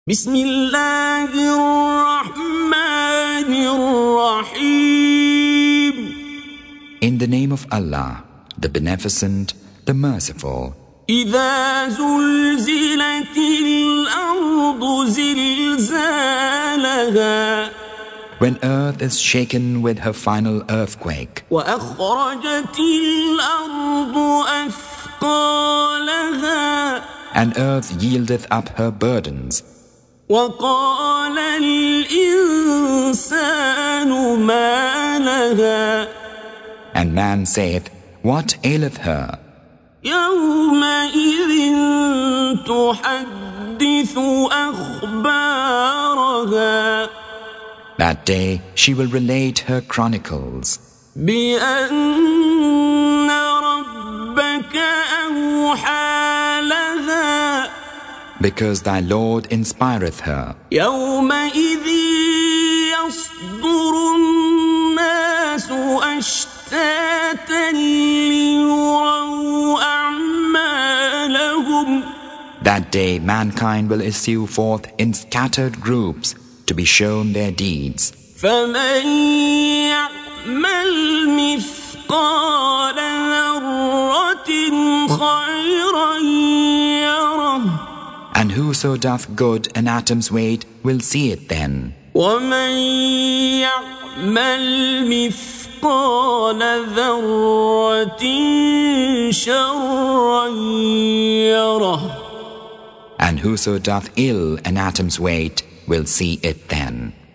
Surah Sequence تتابع السورة Download Surah حمّل السورة Reciting Mutarjamah Translation Audio for 99. Surah Az-Zalzalah سورة الزلزلة N.B *Surah Includes Al-Basmalah Reciters Sequents تتابع التلاوات Reciters Repeats تكرار التلاوات